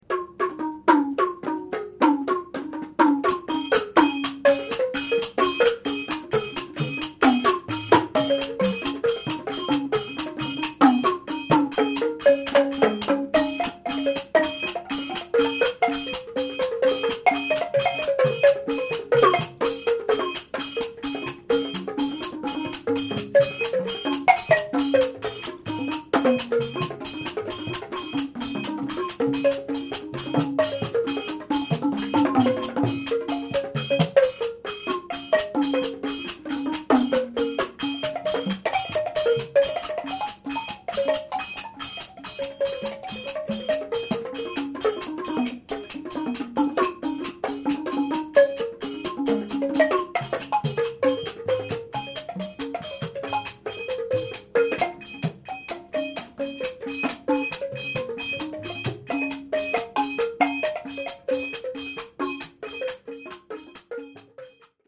pat-waing). The drums are beaten with the hands. The drums are hung vertically within the frame so that the musician plays on the upper heads only.